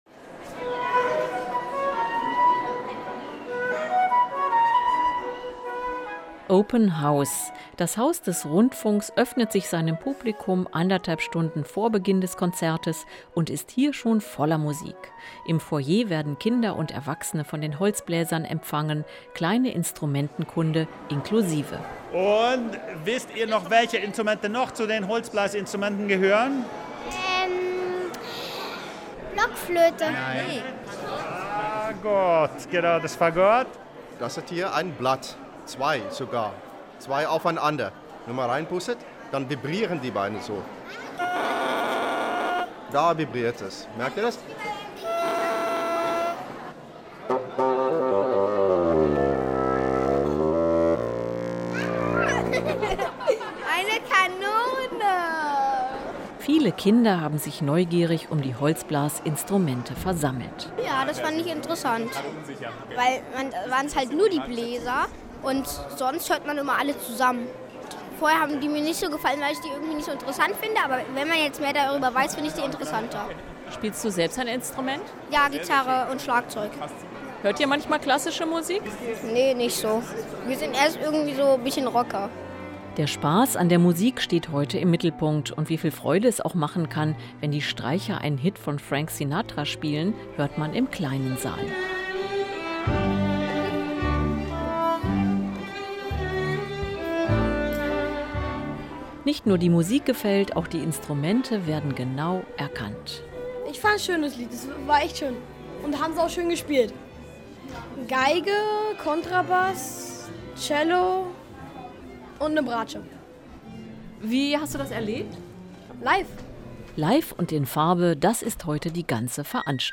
Bei den Kinderkonzerten von rbbKultur ist Mitmachen erwünscht. Am Sonntag hat die besondere Konzertreihe im Haus des Rundfunks Jubiläum gefeiert. Bei der 100. Ausgabe bekamen die Kinder dank "Pizza" und "Hamburger" Rhythmusgefühl.